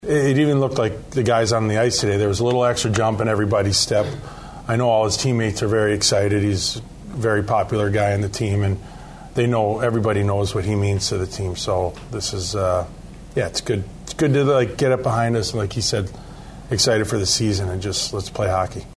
Wild General Manager Bill Guerin says Kaprizov’s teammates were happy as well with the news.